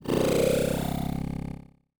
Robotic Buzz Alarm.wav